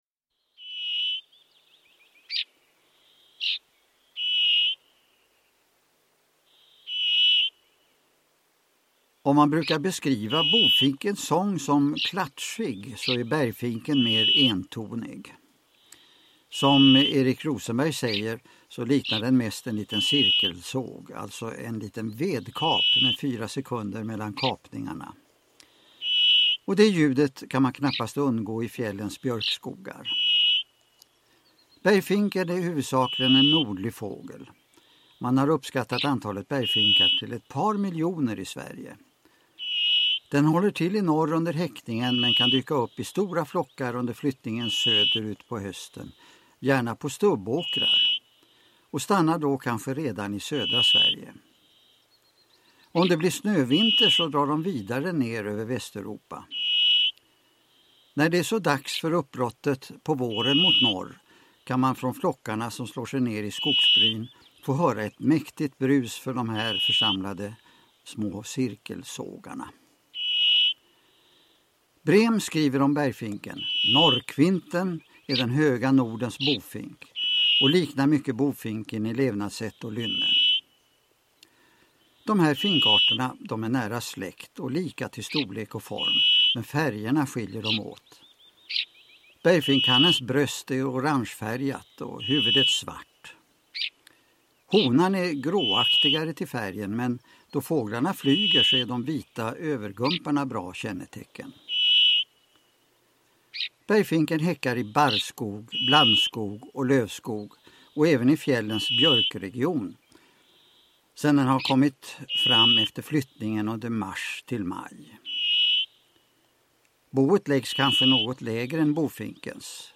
Fåglar från skog, myr och fjäll – Ljudbok – Laddas ner